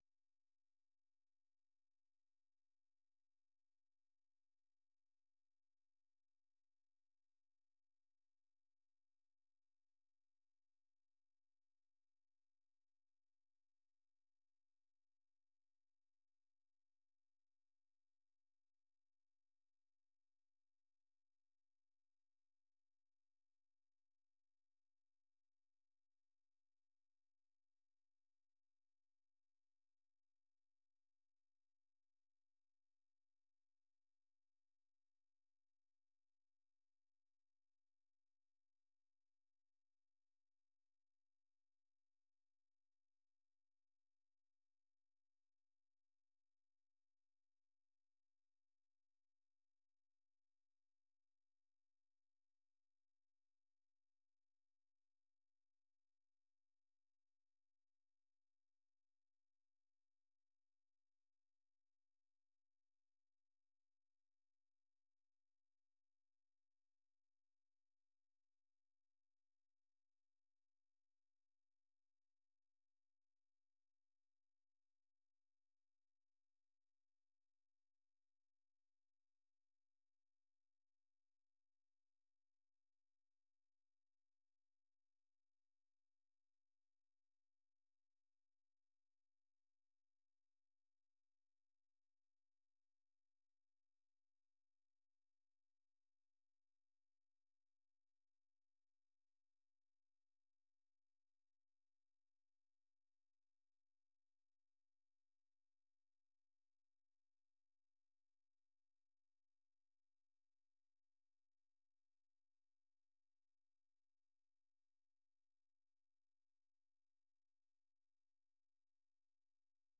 RM Show - French du blues au jazz